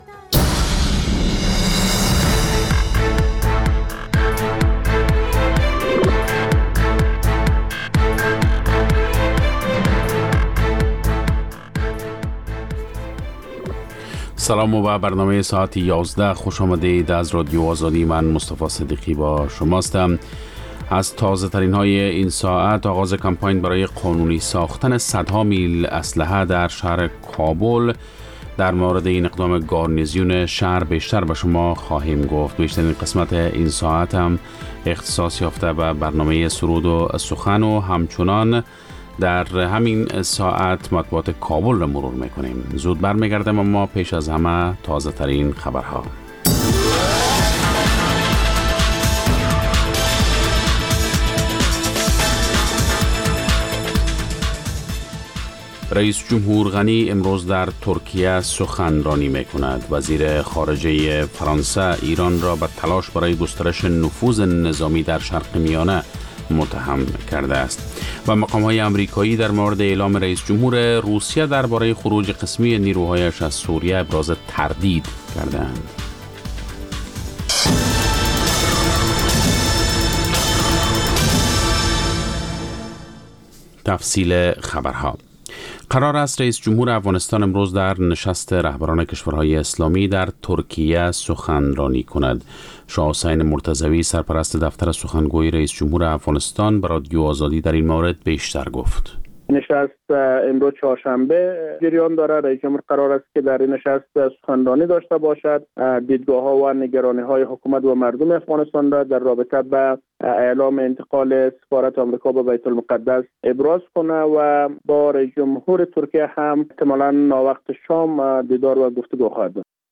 خبر ها و گزارش‌ها، سرود و سخن